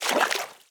Footstep_Water_01.wav